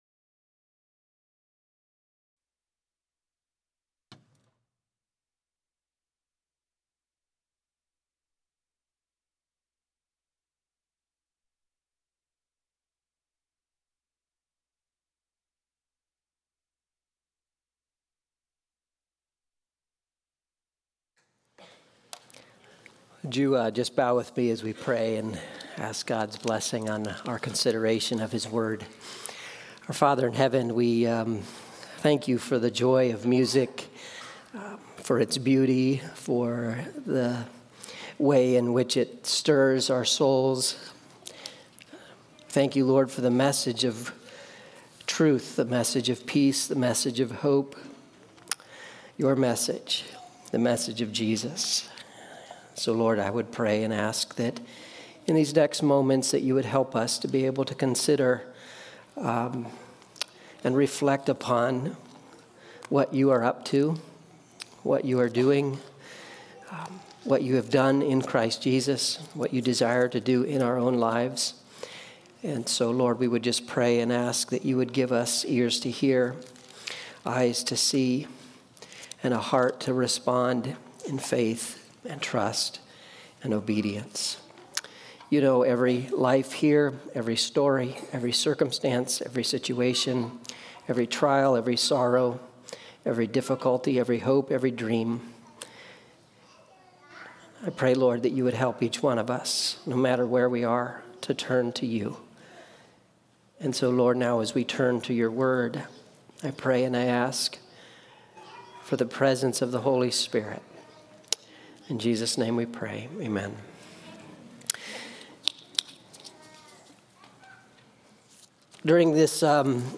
Sermons | Staunton Alliance Church